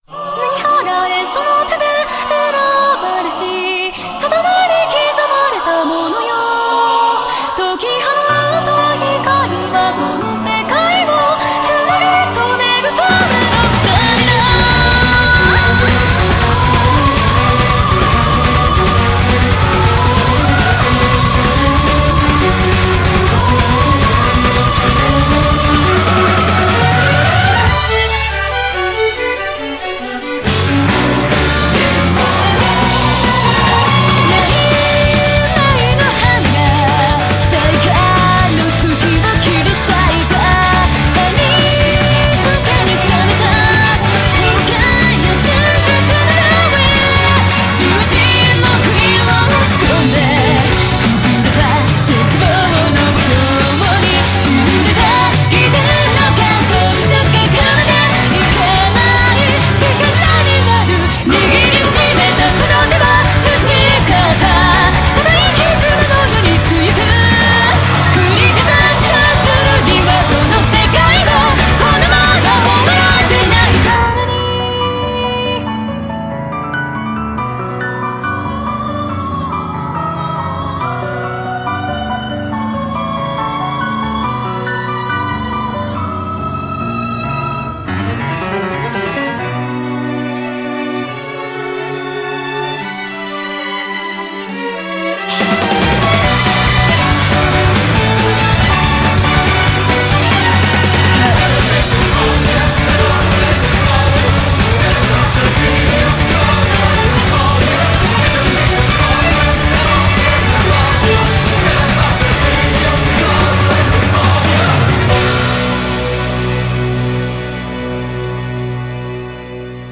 BPM125-140
Audio QualityPerfect (High Quality)
Main Theme